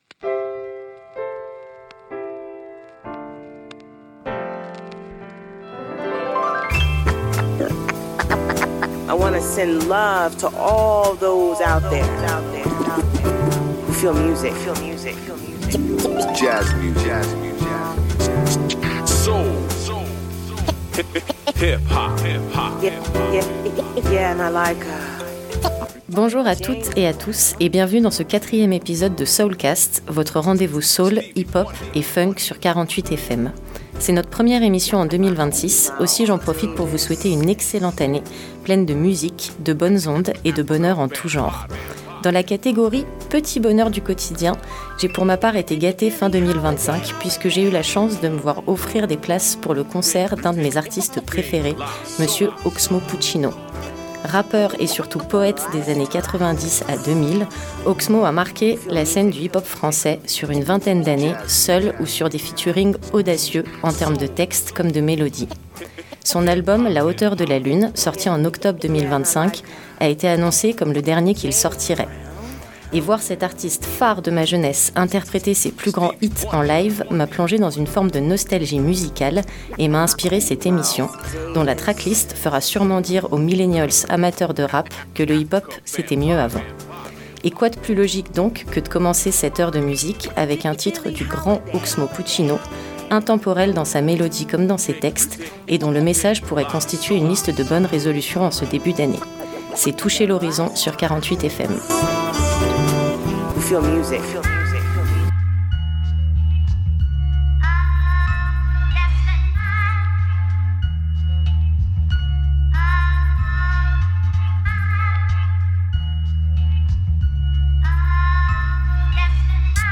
Emission du mardi 20 janvier à 21 h 00